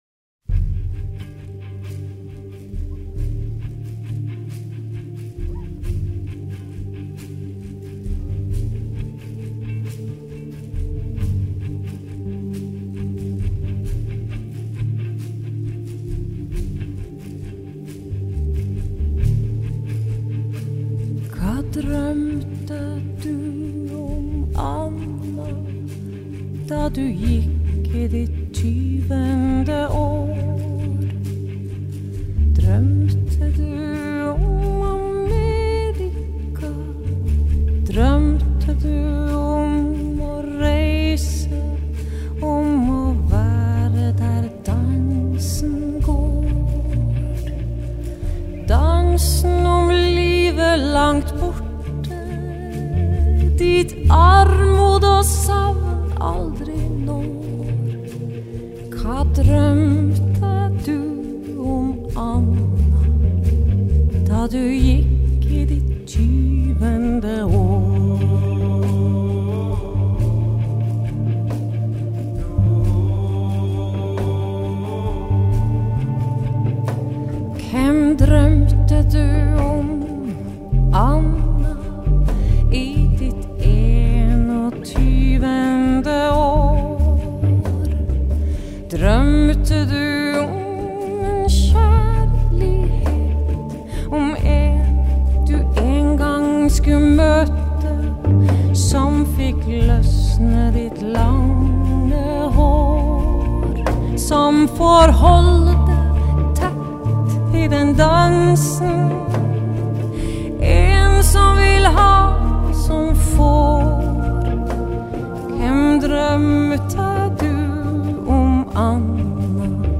Relevant background music
norwegian_and_melancholic.mp3